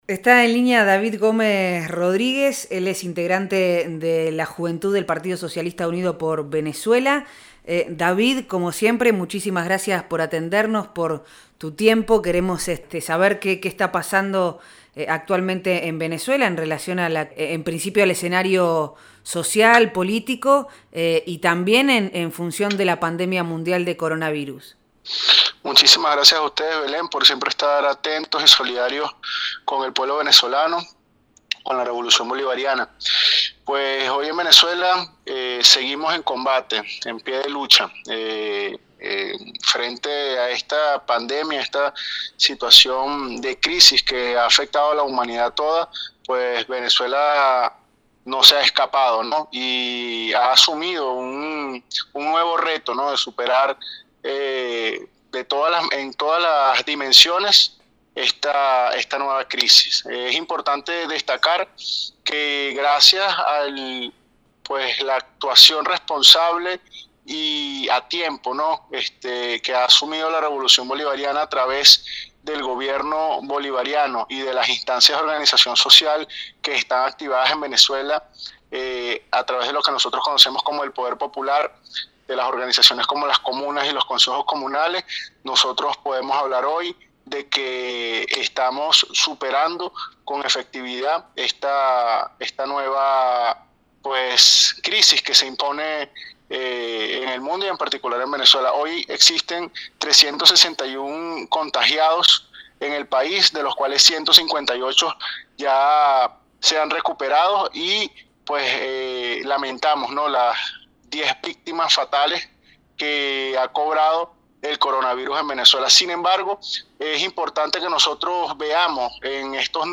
Proyecto Erre Radio , de lunes a viernes de 8 a 10 am por FM Vox Populi, 100.1; y por FM Del Barrio, 98.1